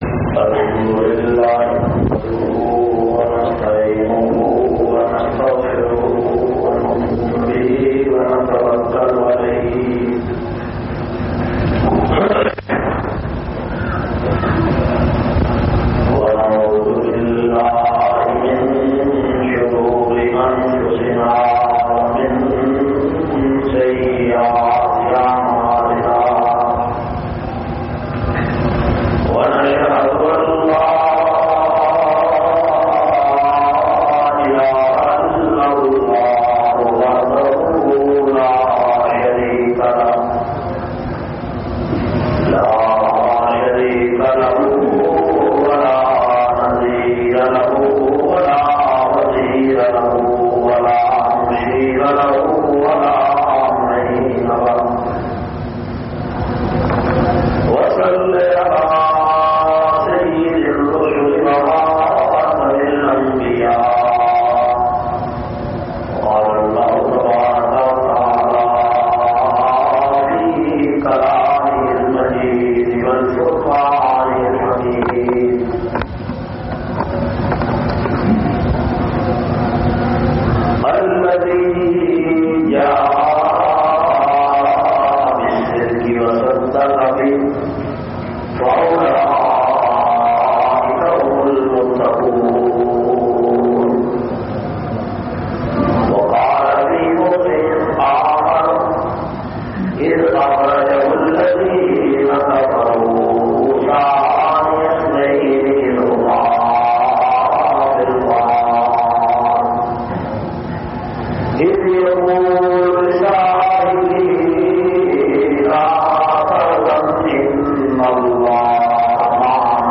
513- Shan Abu Bakr Sideeq Jumma khutba Jamia Masjid Muhammadia Samandri Faisalabad.mp3